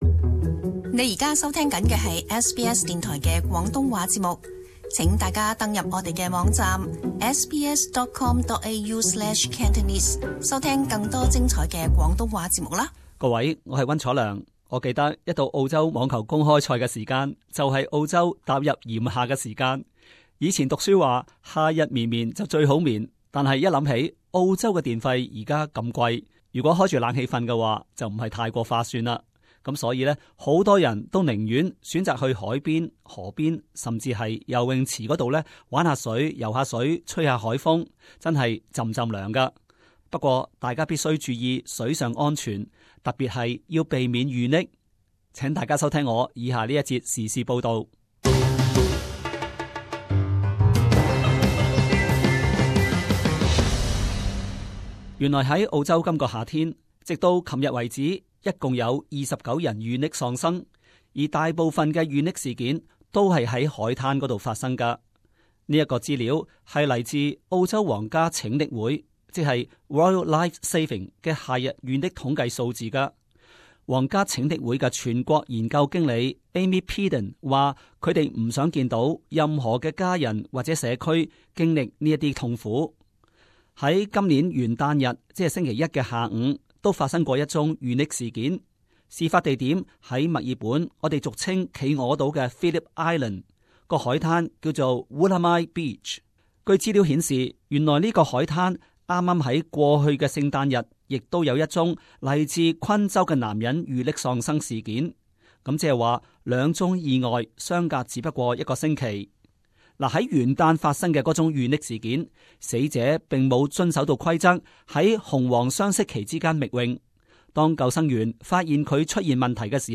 【時事報導】 記緊在紅黃旗之間游泳